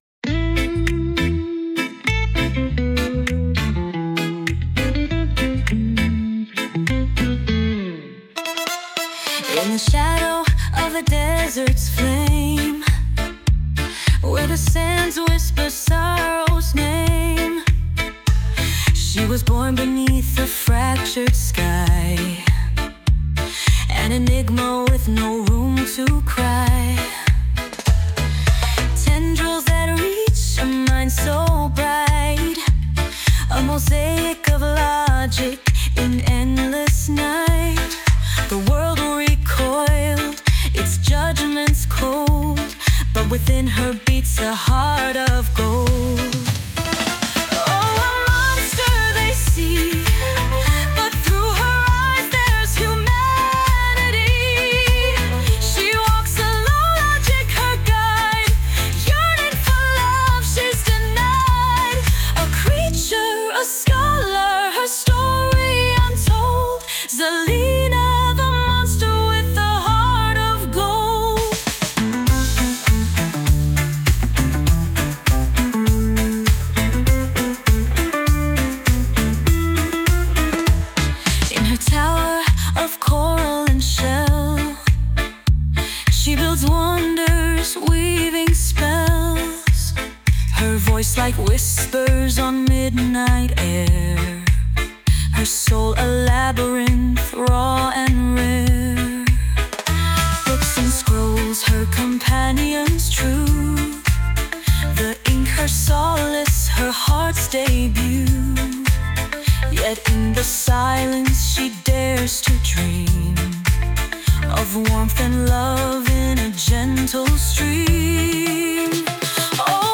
Her theme. I am the female singer/song writer